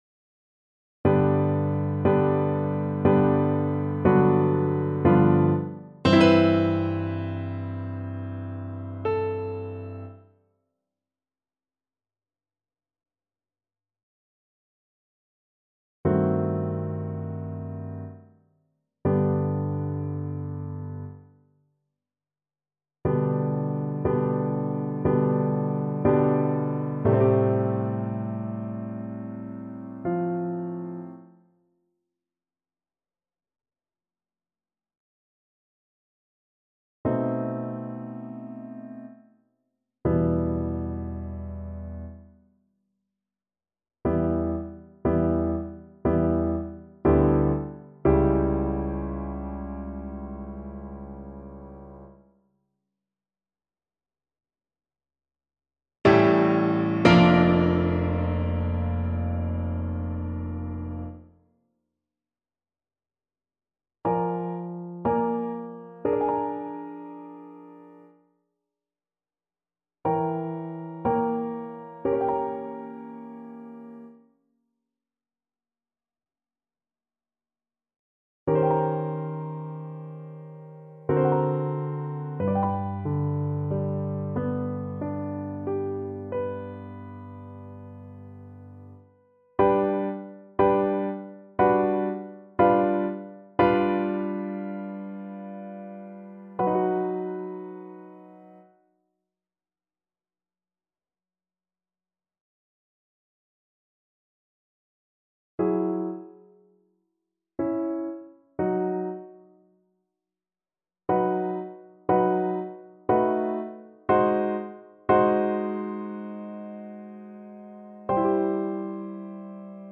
3/4 (View more 3/4 Music)
~ = 60 Langsam, leidenschaftlich
Classical (View more Classical Cello Music)